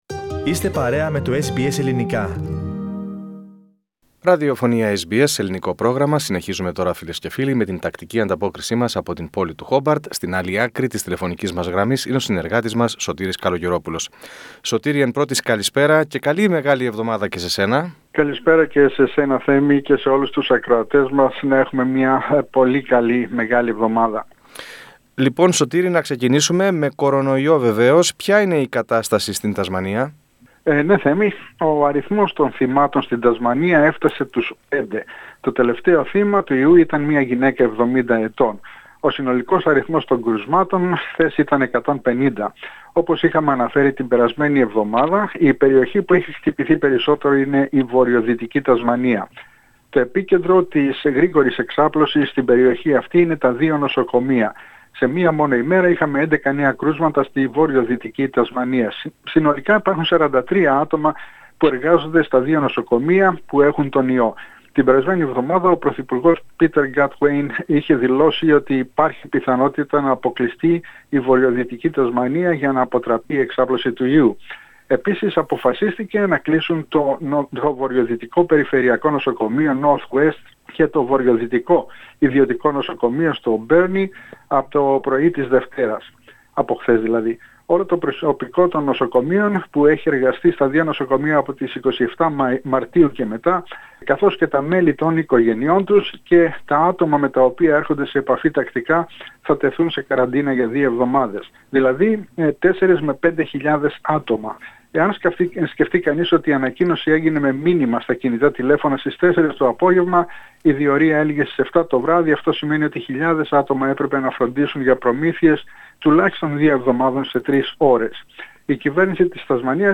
Περισσότερα στην ανταπόκριση